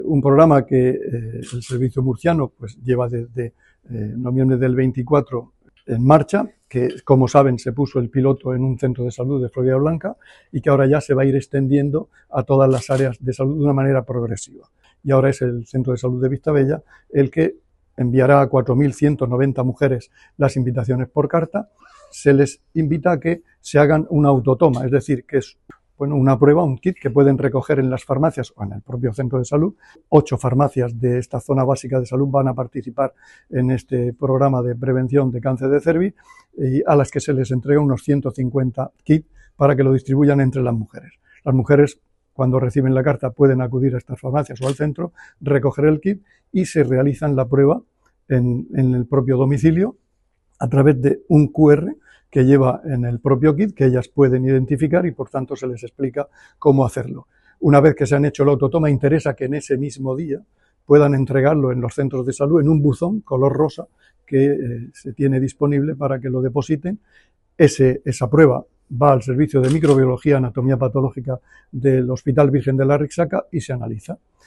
El consejero de Salud, Juan José Pedreño, presentó la extensión del programa de cribado de cáncer de cérvix al centro de salud Vistabella de Murcia.[mp3]